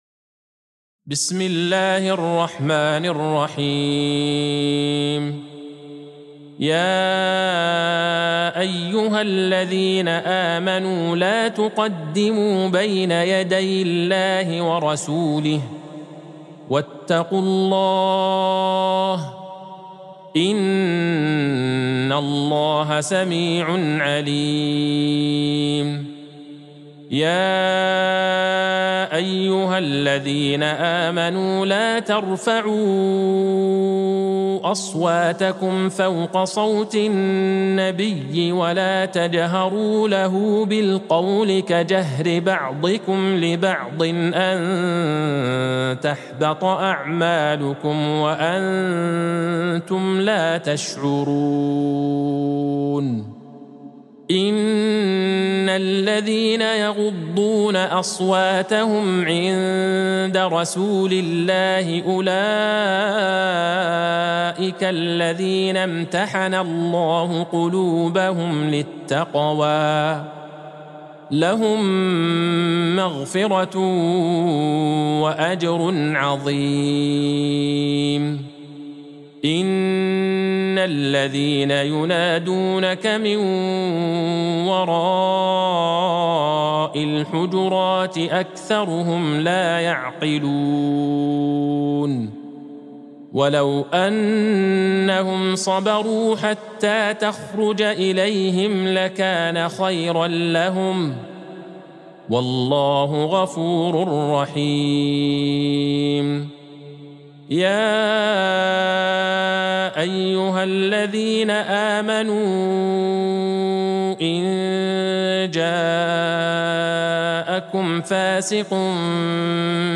سورة الحجرات Surat Al-Hujurat | مصحف المقارئ القرآنية > الختمة المرتلة ( مصحف المقارئ القرآنية) للشيخ عبدالله البعيجان > المصحف - تلاوات الحرمين